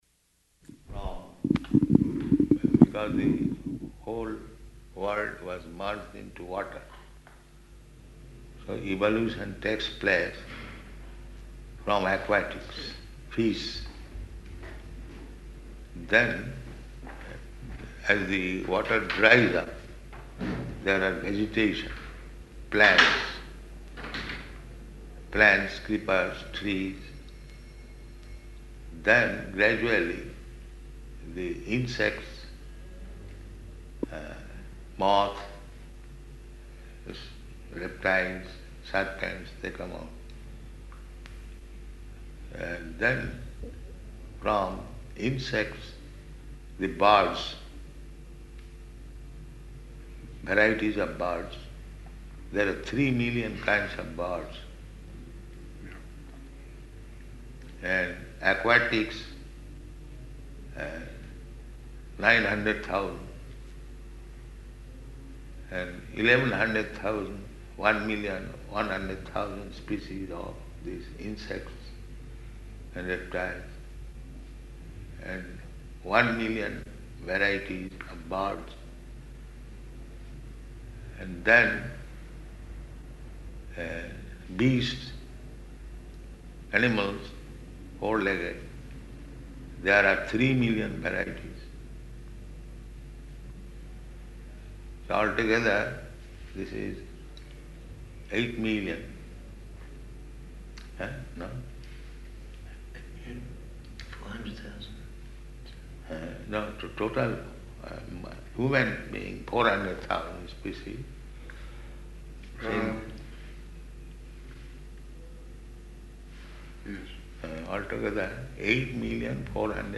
Room Conversation with Journalist
Room Conversation with Journalist --:-- --:-- Type: Conversation Dated: May 19th 1975 Location: Melbourne Audio file: 750519R1.MEL.mp3 Prabhupāda: [indistinct] ...because the whole world was merged into water.